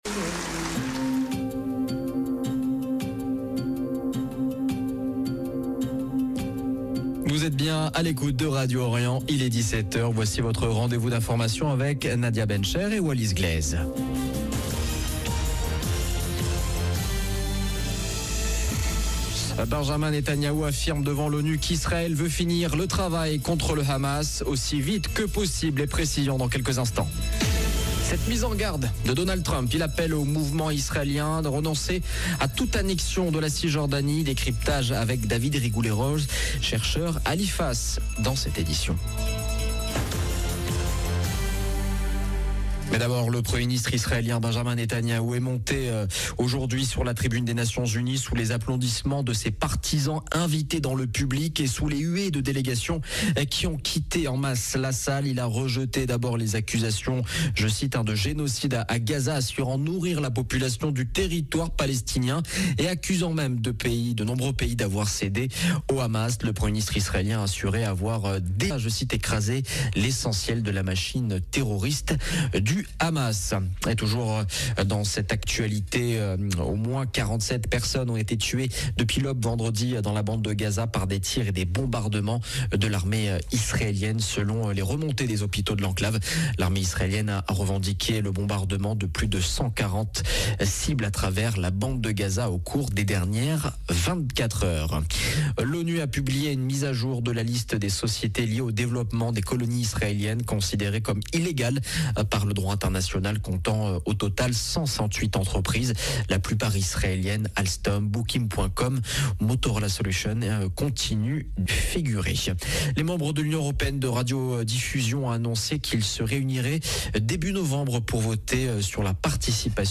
Journal de 17H du 26 septembre 2025